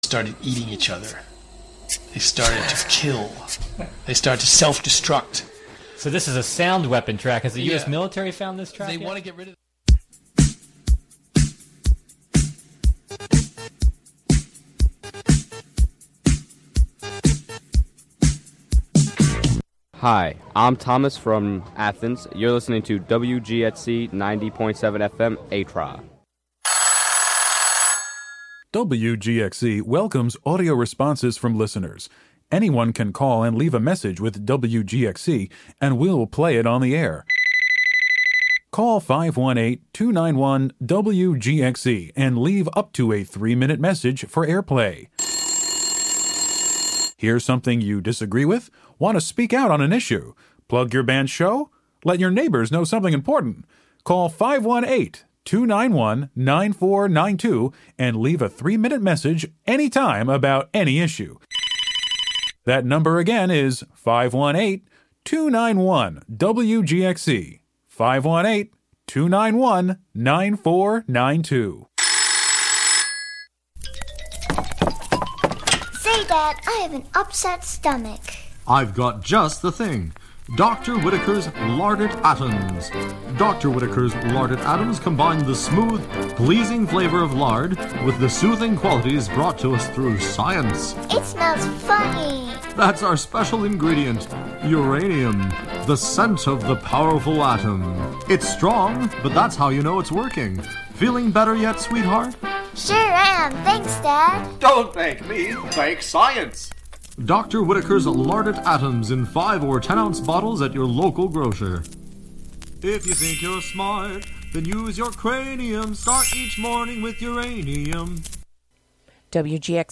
Our container: Radiolab, an open, experimental, youth-led programming and recording space. Show includes local WGXC news at beginning, and midway through.